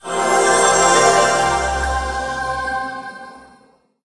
Media:squad_heal_spell_01.wav 技能音效 spell 治疗台治疗音效
Squad_heal_spell_01.wav